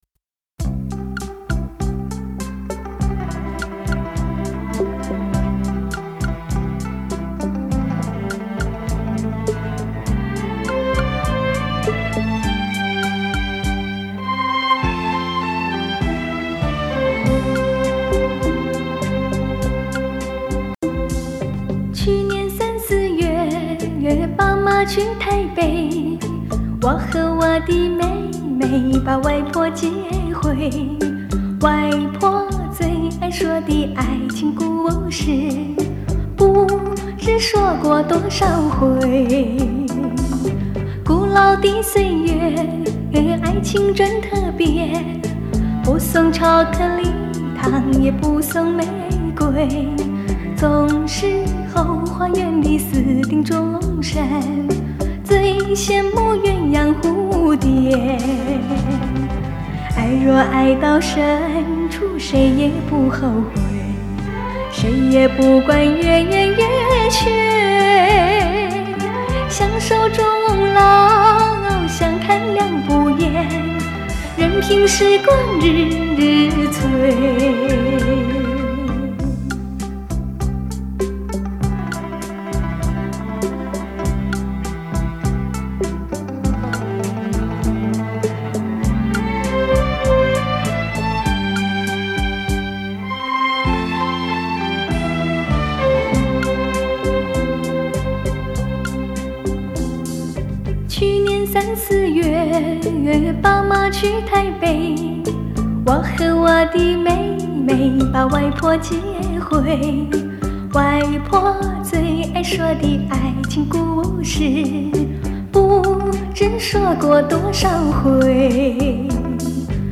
触摸可及的人声带来“绝对空间”体验！